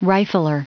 Prononciation du mot rifler en anglais (fichier audio)
Prononciation du mot : rifler